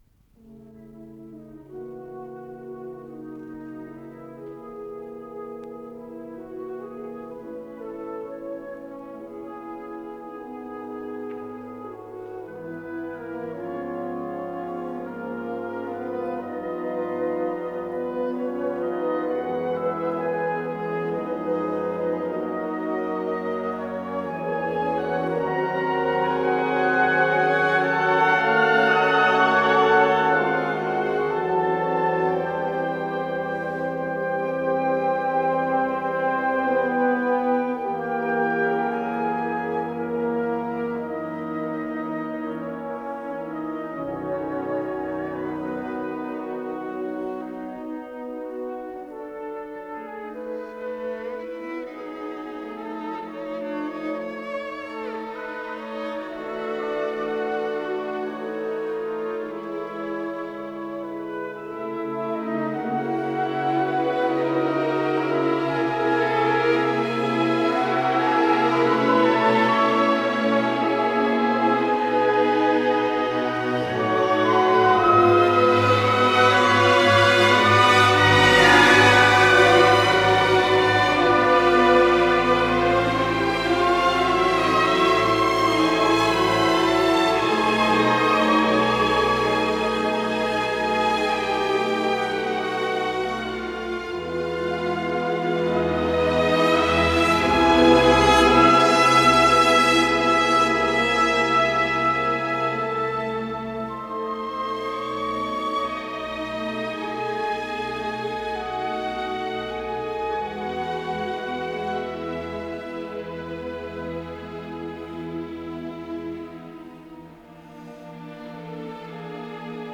Исполнитель: Большой симфонический оркестр Всесоюзного радио и Центрального телевидения
до минор